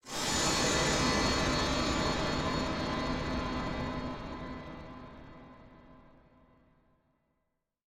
ATMOS Horror / Creepy "Ding"
atmos atmosphere background-sound creepy dark ding film Gothic sound effect free sound royalty free Sound Effects